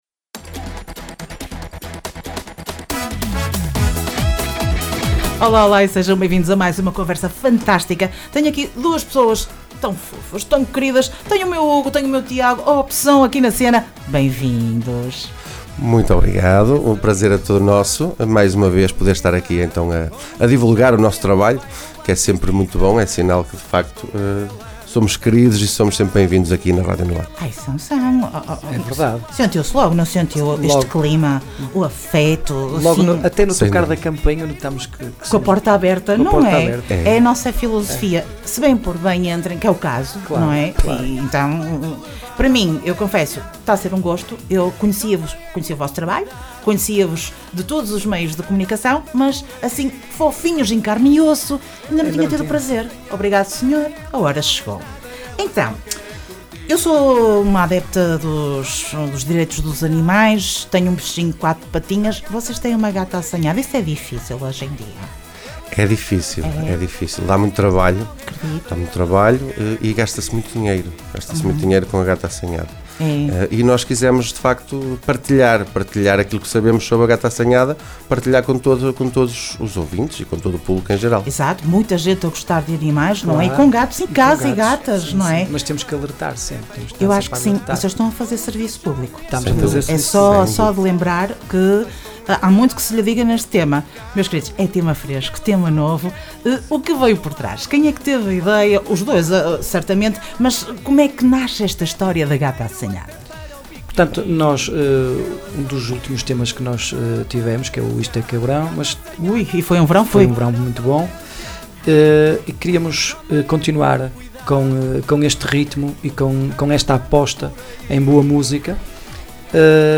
Entrevista Opsom dia 09 de Junho.
ENTREVISTA-OPSOM-JUN2025.mp3